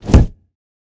Massive Punch A.wav